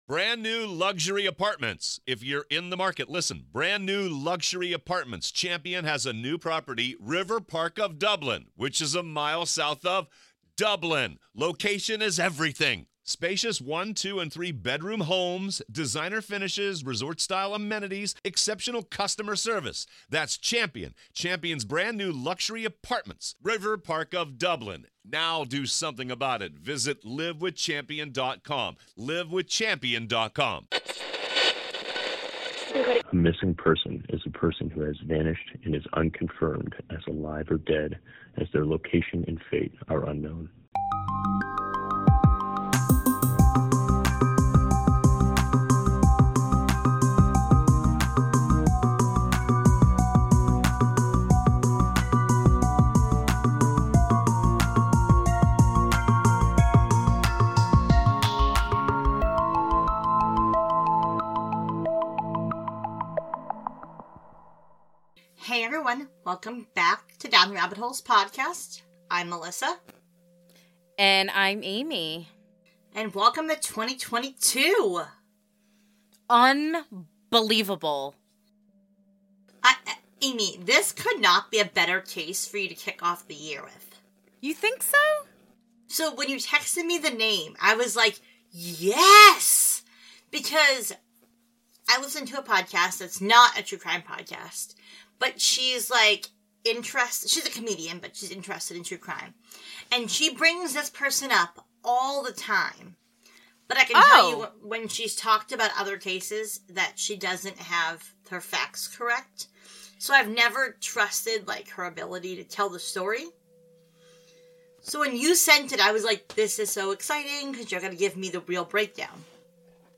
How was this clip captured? **Note, there are some audio issues